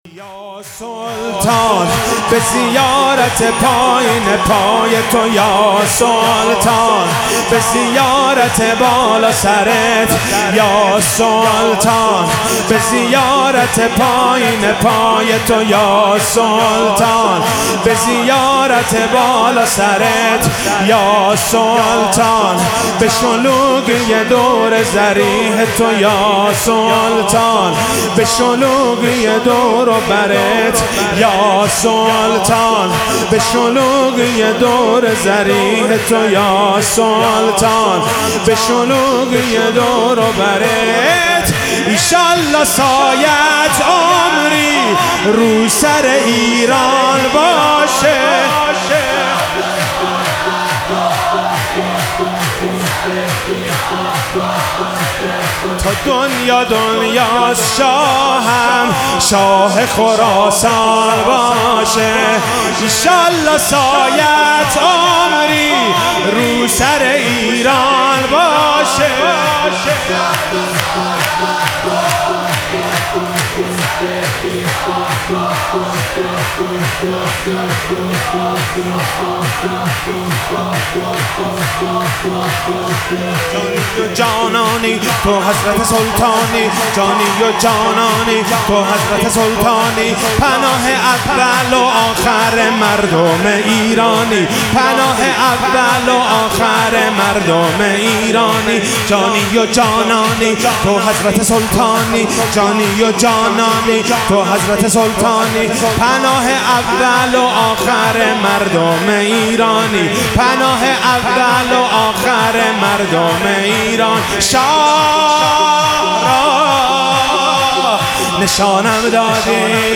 ولادت امام رضا علیه السلام